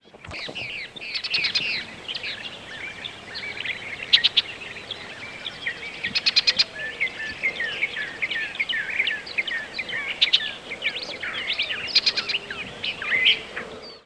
Red-winged Blackbird diurnal flight calls
Two apparently distinct call types given in alternating series by bird in flight.
Northern Mockingbird and Long-billed Thrasher in the background.